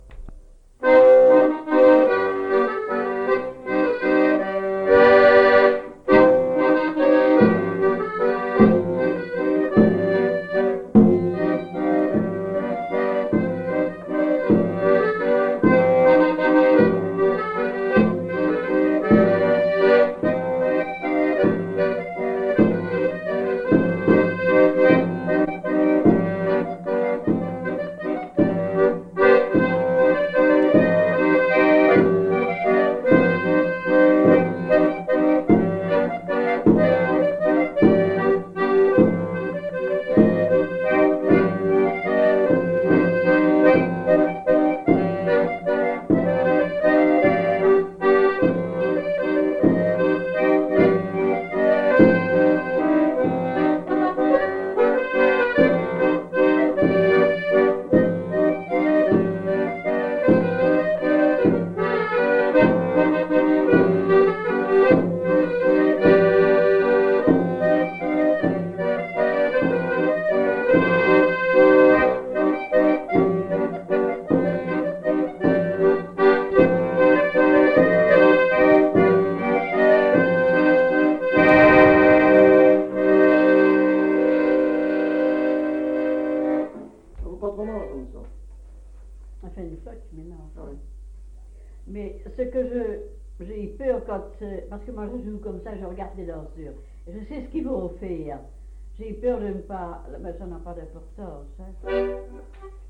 Elle jouait de l'accordéon chromatique, notamment dans un groupe folklorique.
Passe-pied
Type : passe-pied | Date : années 1970